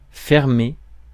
Ääntäminen
IPA : /ʃʌt/